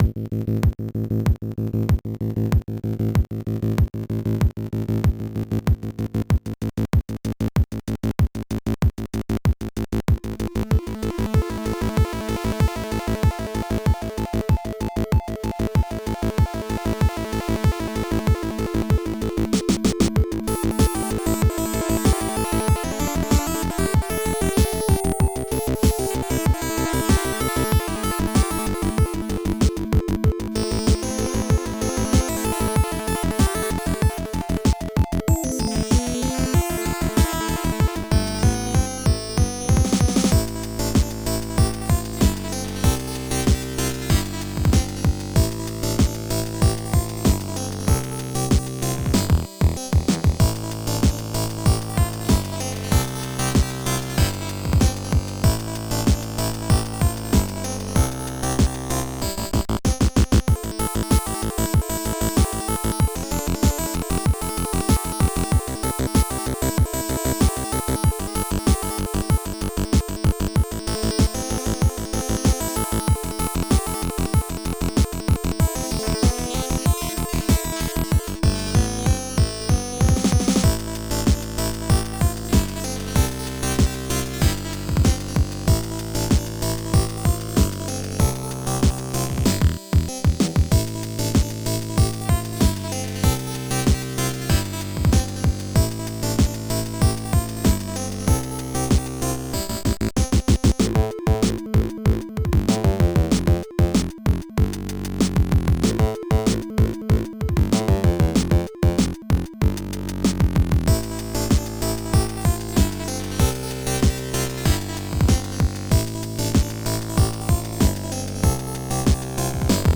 Tracked Music